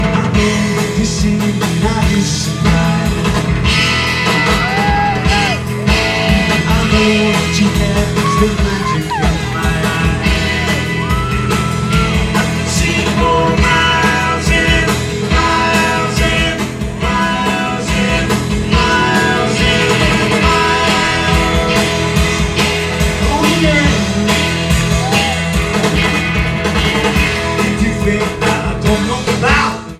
Comments: Very good mono soundboard recording*.